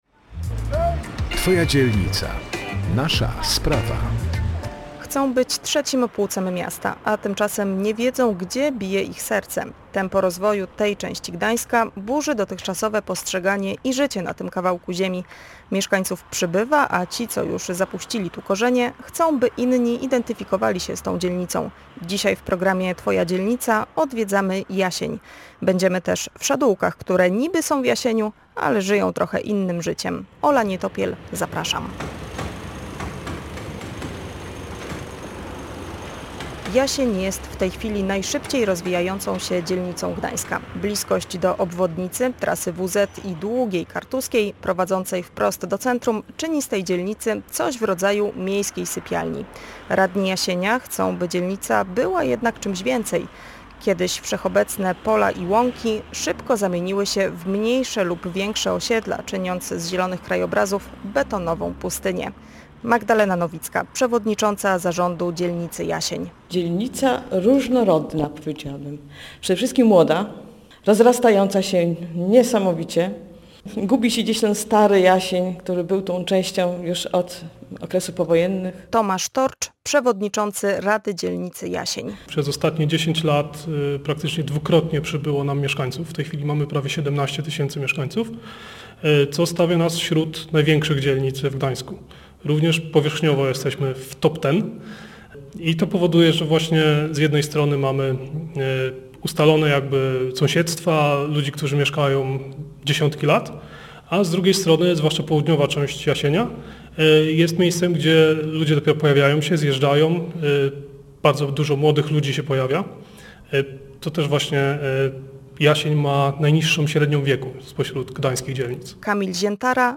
W programie Twoja Dzielnica zajrzymy do Jasienia, będziemy też w Szadółkach. Porozmawiamy z mieszkańcami, radnymi i z … harcerkami ze Szczepu Jasień.